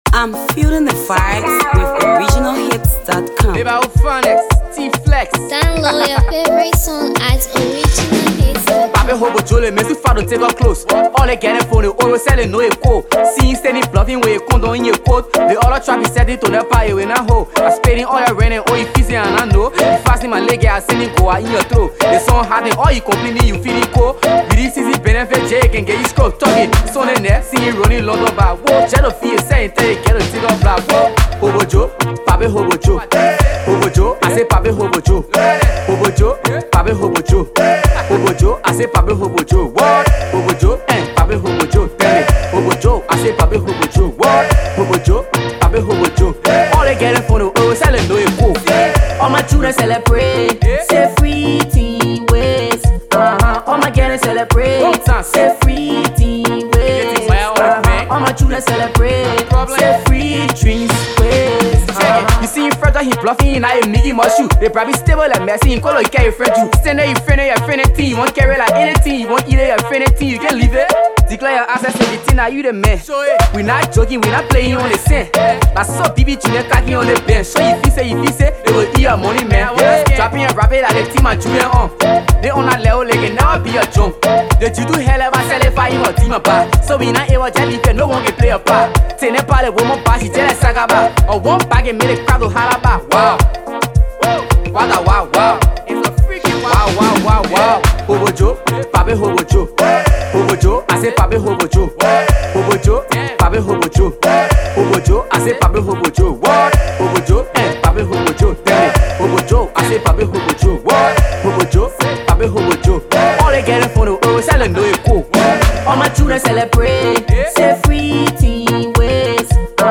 latest studio effort
Afro Pop